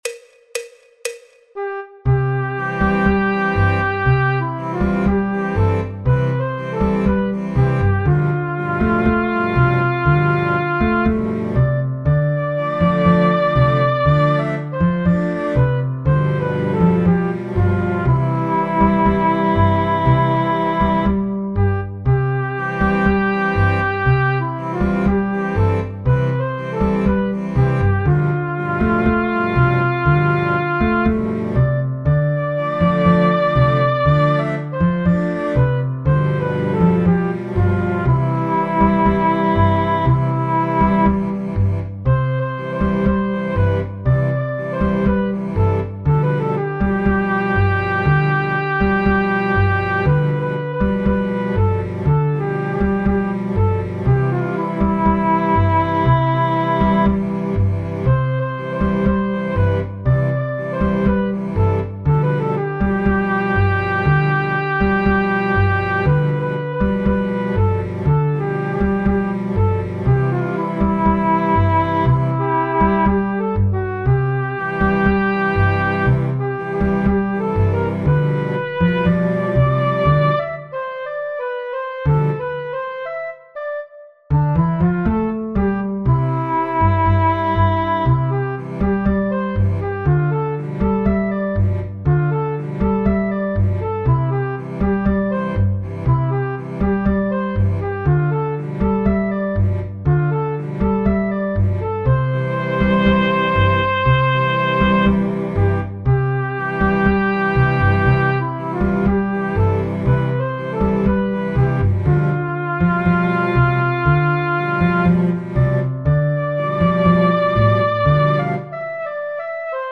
Habanera, Música clásica